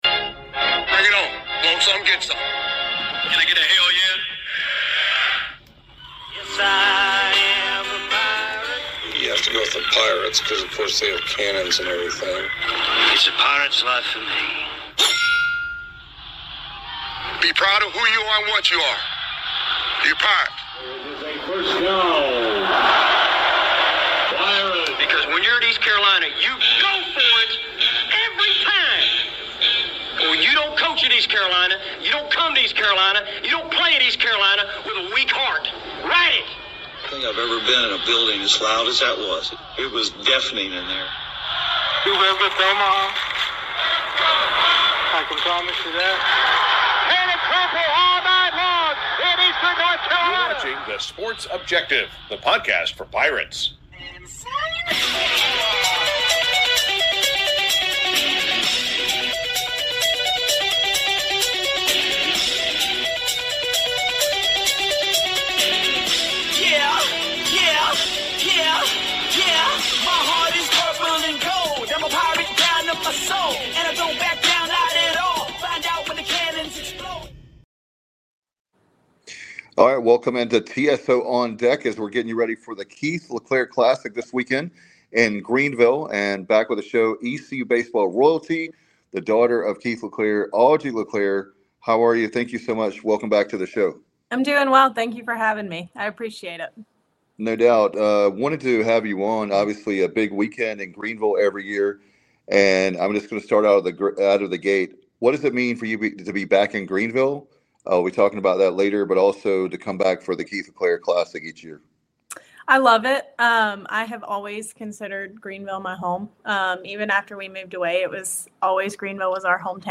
Our sit down interview